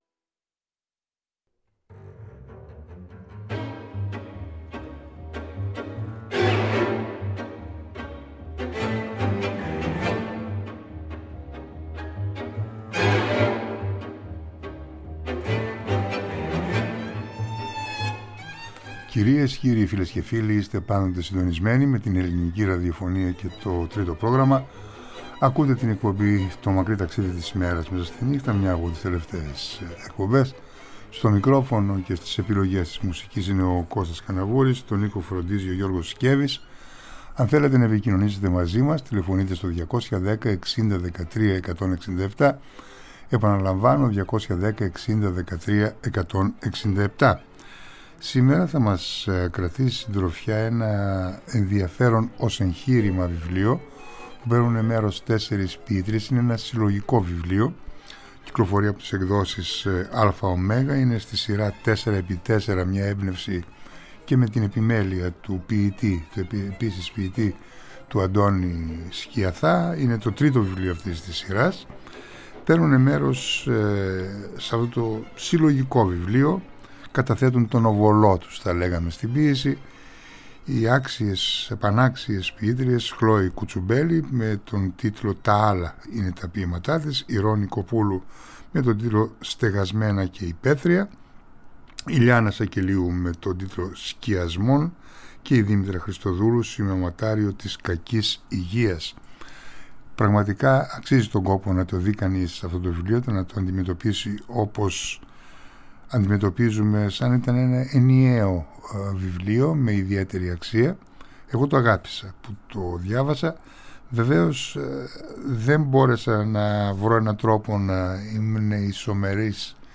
τα ποιήματα της αποψινής εκπομπής, σε απευθείας μετάδοση από το Τρίτο Πρόγραμμα.